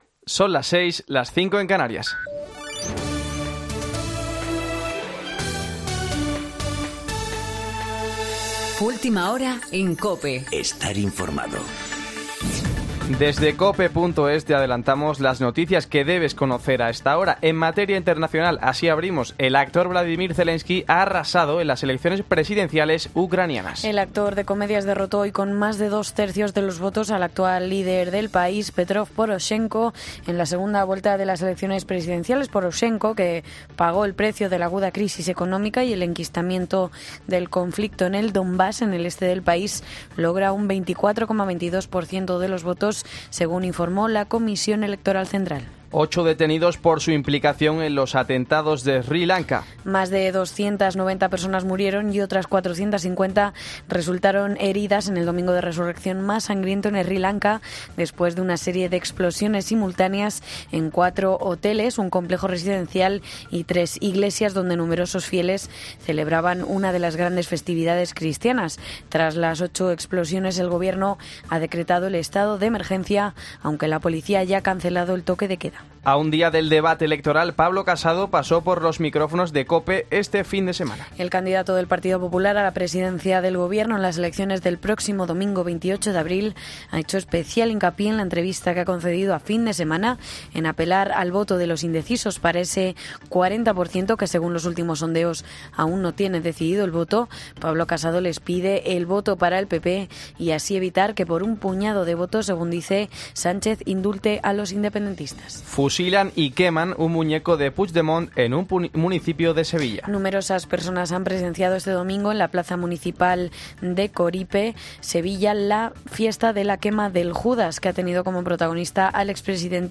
Boletín de noticias de COPE del 22 de abril a las 06.00